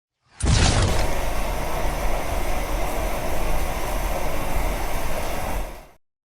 Jetpack Ignite
yt_wGHRQ5dkgy8_jetpack_ignite.mp3